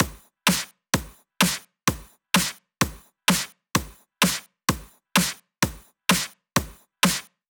VDE 128BPM Renegade Drums 6.wav